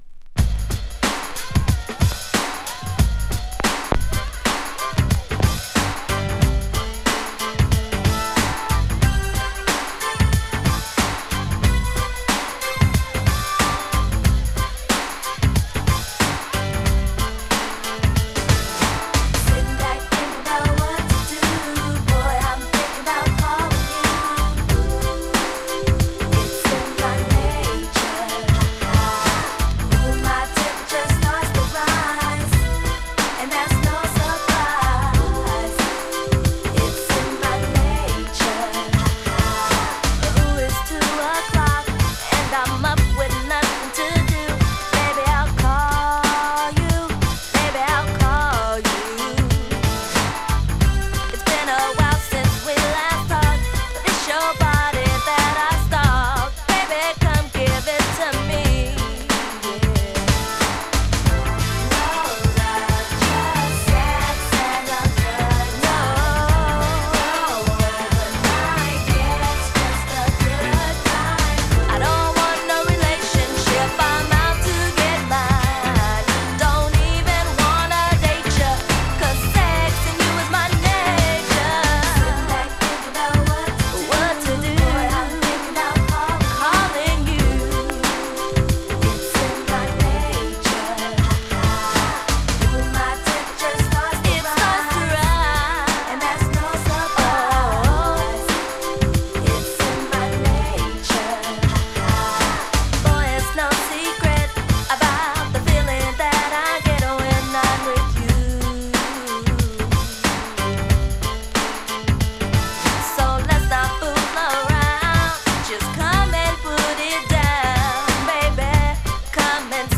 HIP HOP SOUL人気曲。